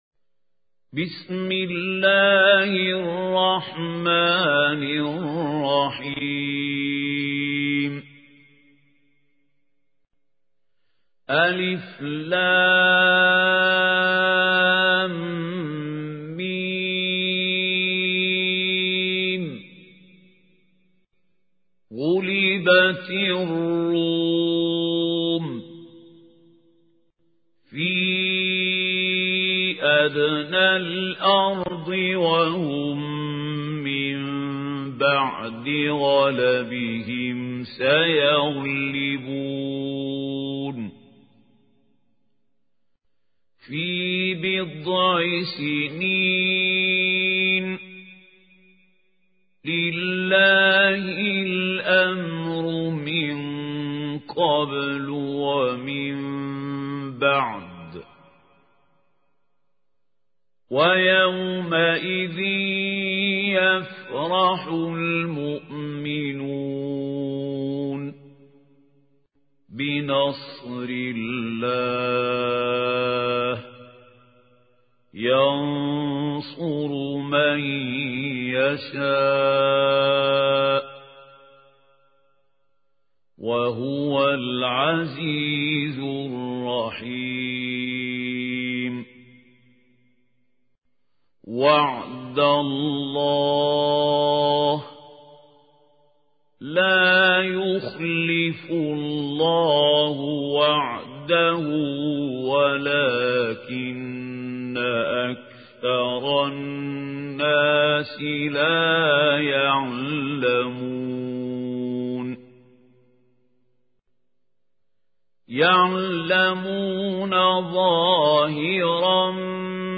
القارئ: الشيخ خليل الحصري